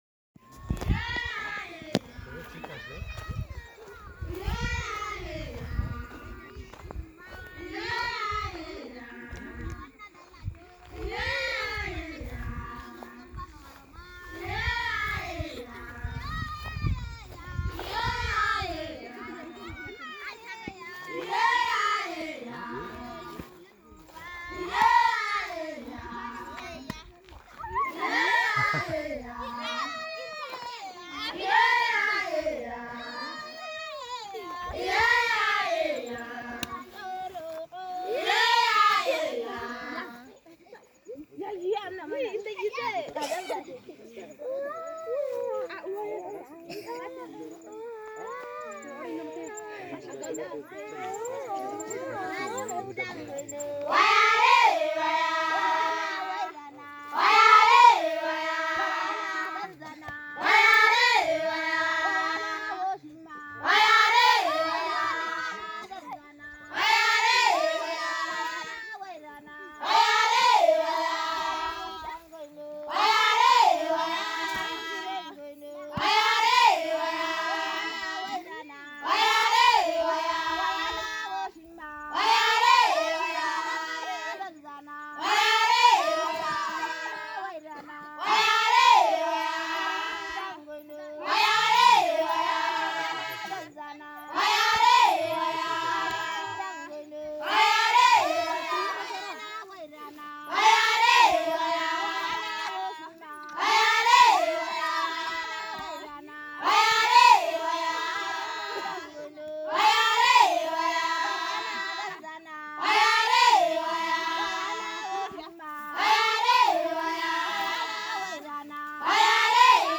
Cantos hamer por la noche 1
Cantos-hamer-por-la-noche-1.m4a